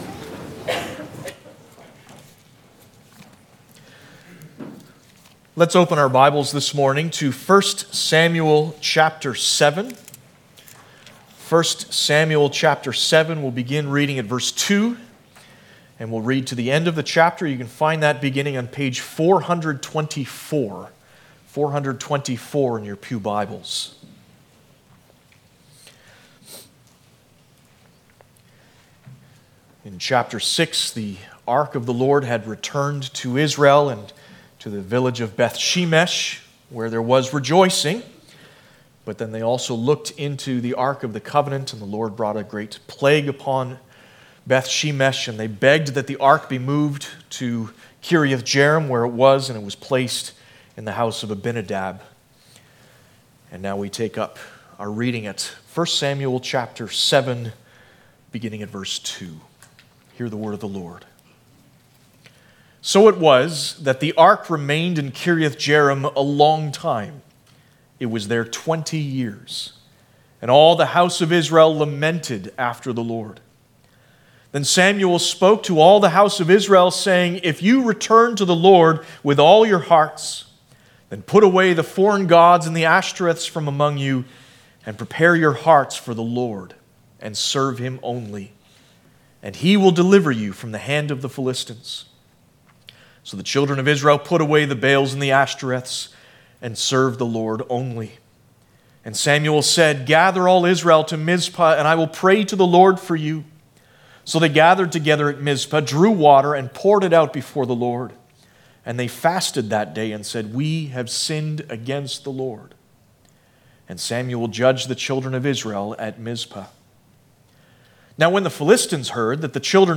Passage: 1 Samuel 7:2-17 Service Type: Sunday Morning